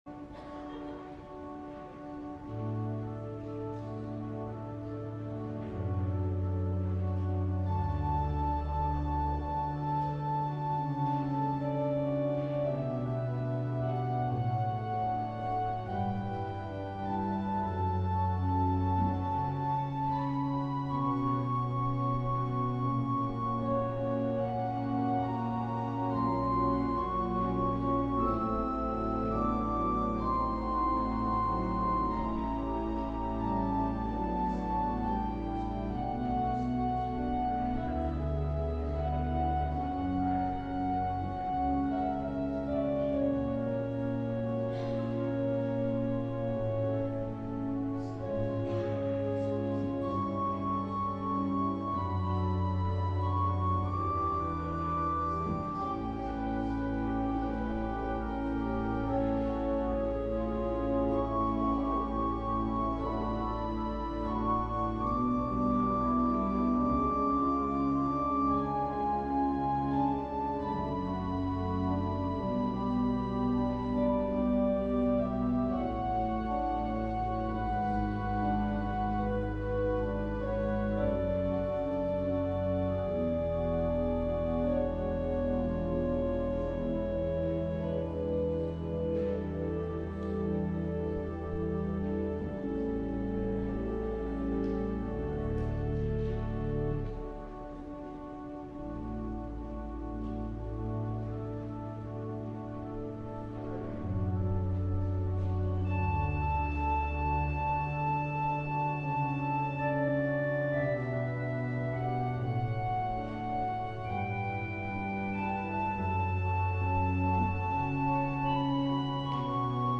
LIVE Morning Service - More Than Merely Human